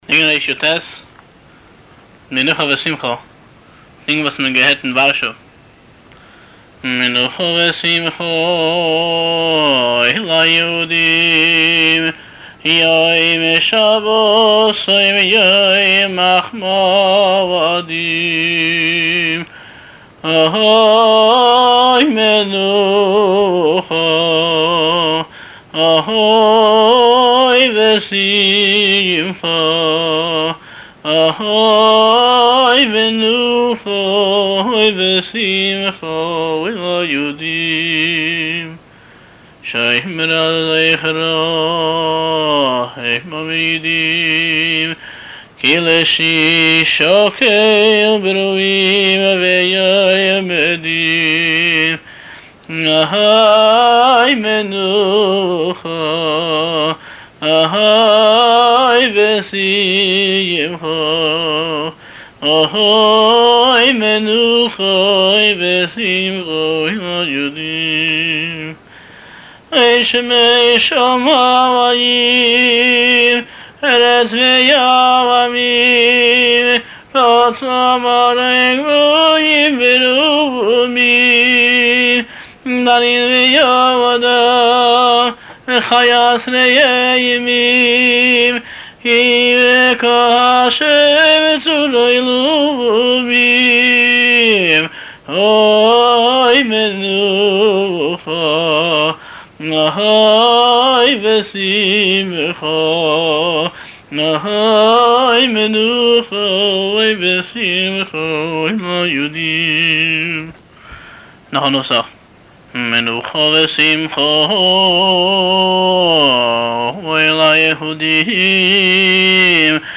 הבעל-מנגן